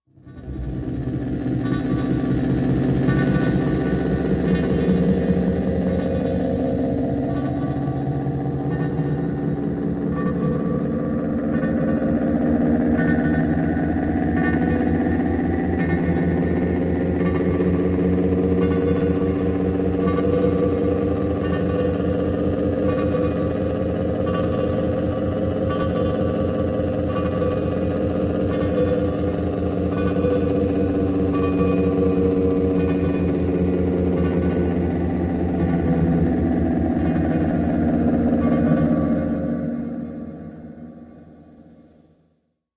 Pulsing
Early Detection, Machine, Space Pulse, Engine Drone, Sonar